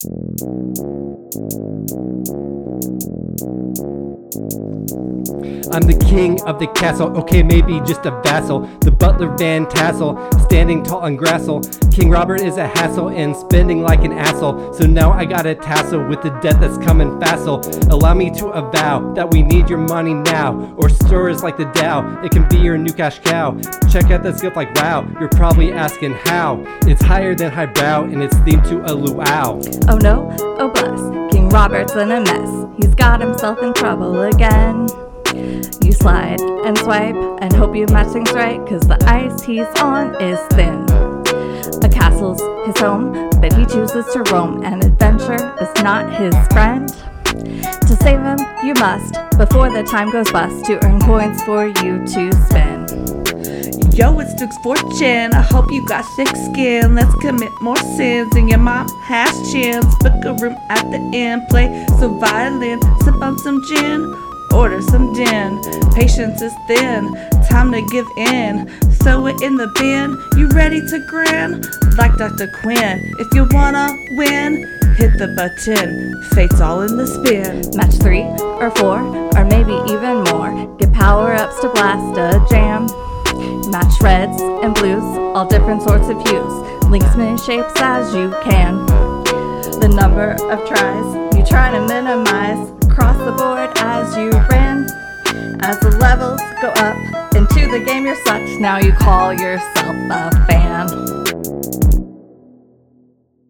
Rap from Episode 78: Royal Match – Press any Button
Royal-Match-Rap.mp3